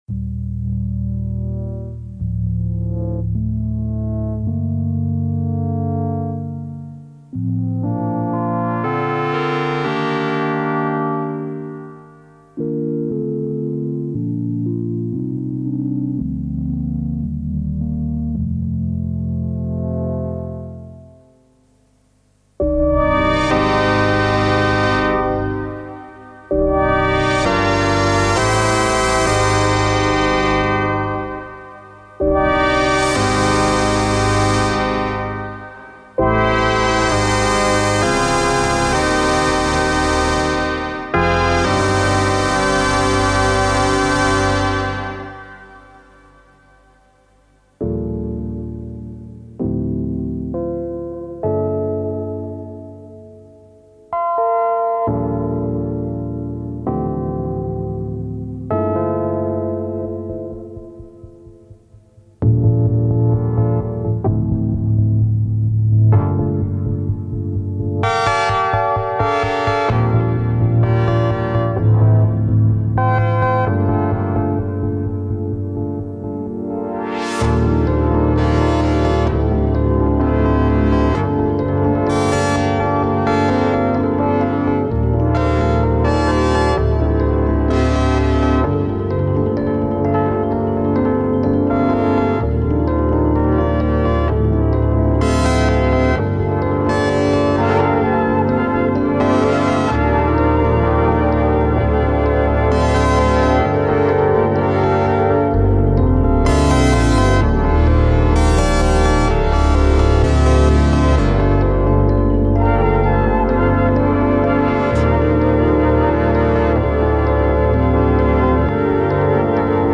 This is a live-in-studio performance